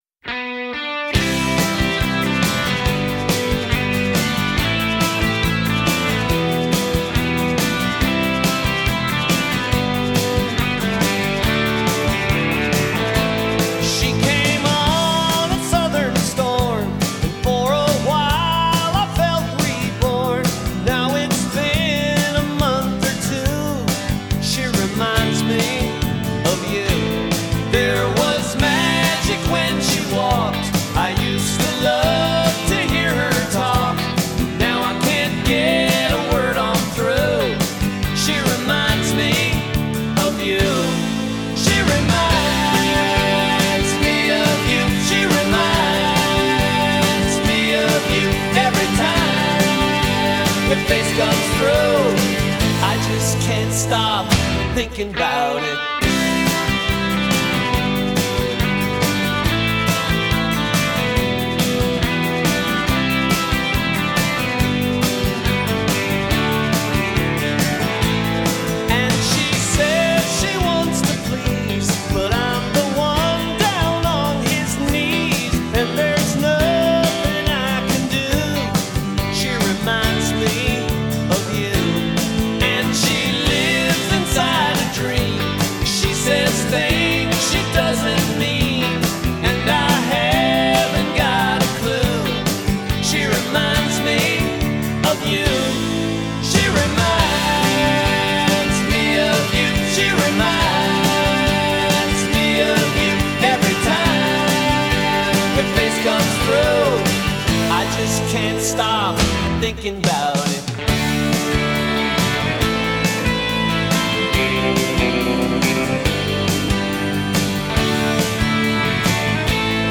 western poprock sound